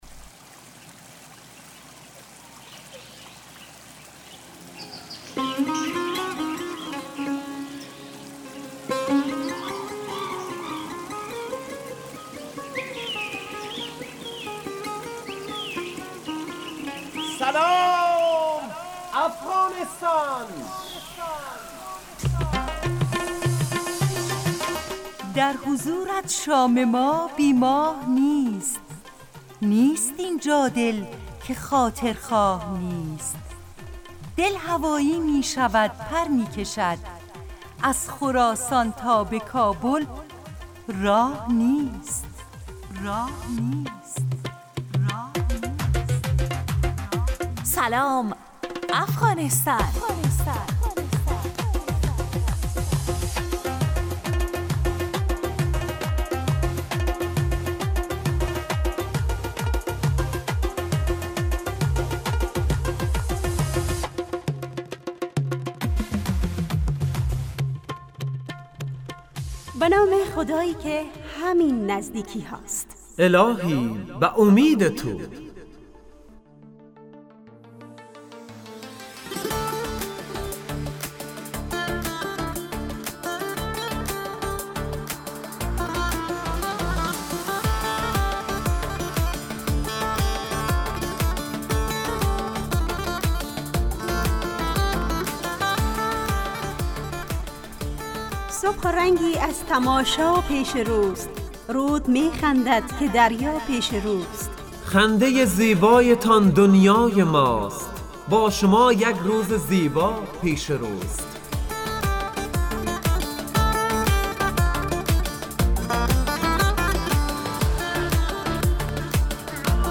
برنامه صبح گاهی رادیو دری به نام سلام افغانستان با موضوع این هفته خوشبختی